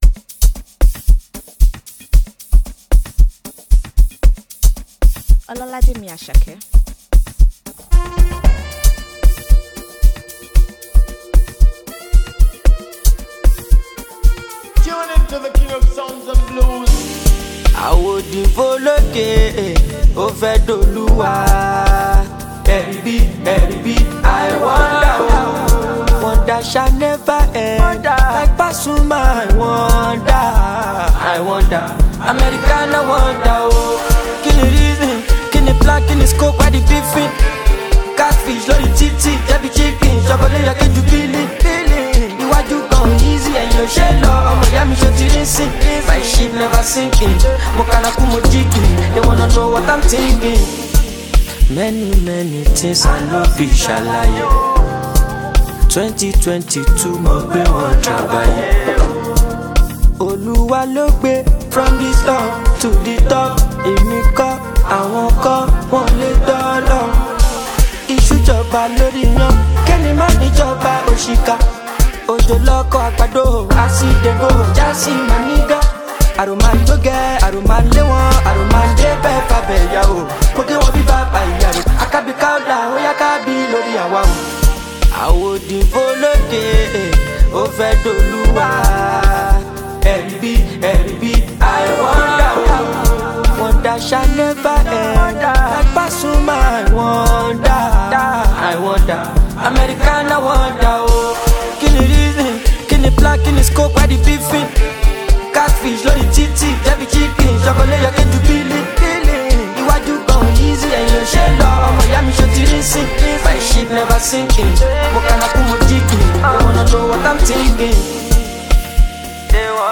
blends Afrobeat with contemporary sounds
With its upbeat tempo and catchy sounds